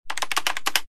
Keyboard4.wav